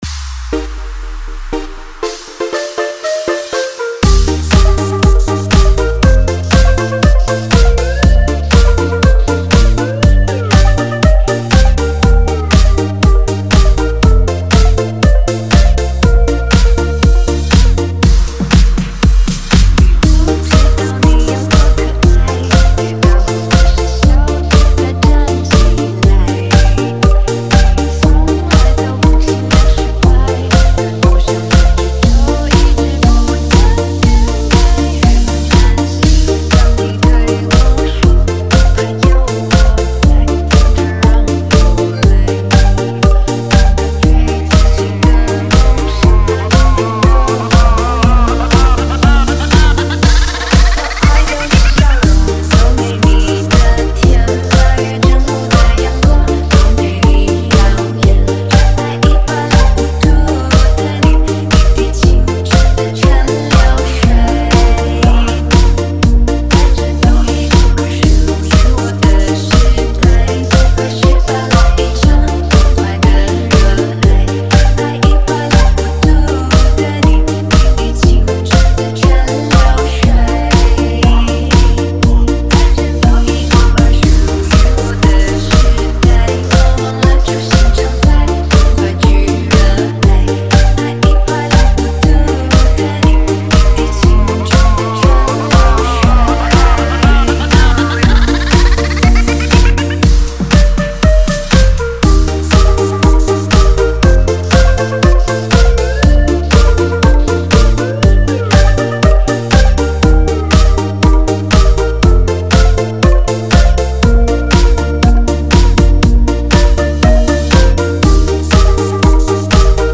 5天前 DJ音乐工程 · ProgHouse 4 推广